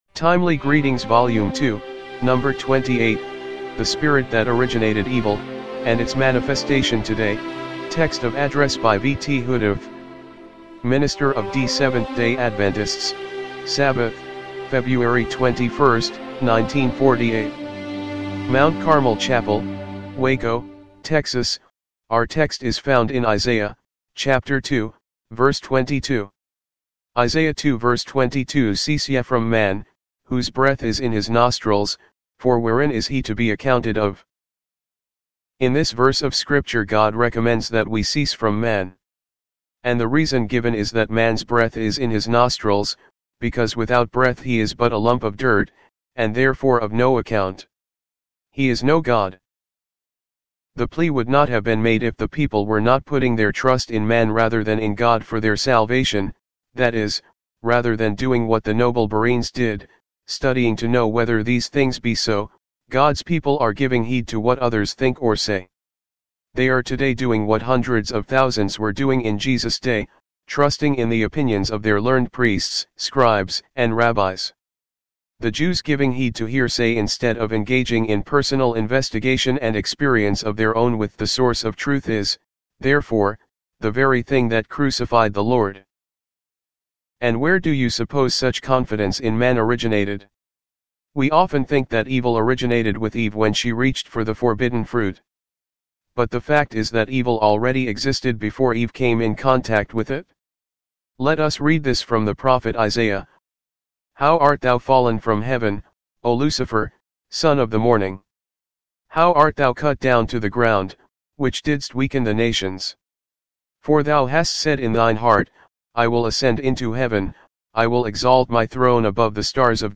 1948 MT. CARMEL CHAPEL WACO, TEXAS